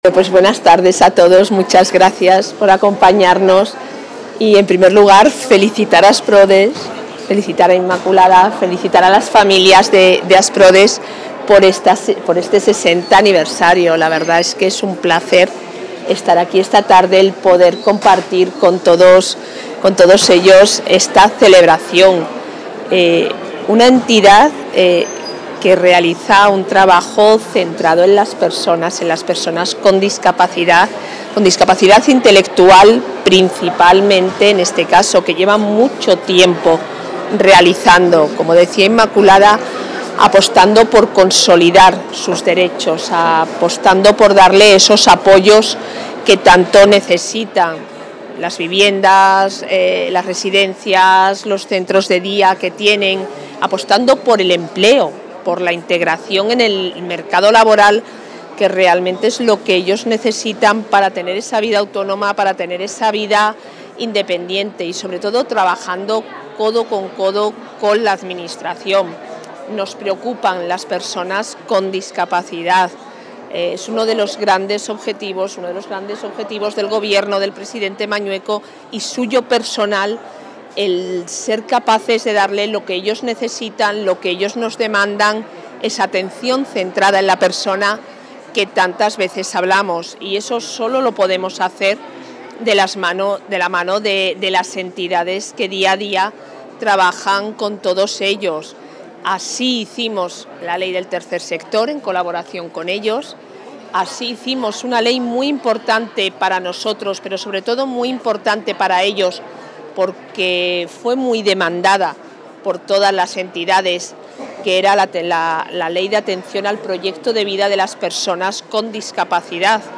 Declaraciones de la vicepresidenta de la Junta.
La vicepresidenta del Ejecutivo autonómico y consejera de Familia e Igualdad de Oportunidades interviene en la gala del 60º aniversario de la entidad en Salamanca, donde ha destacado su papel fundamental en la prestación de apoyos y servicios al colectivo.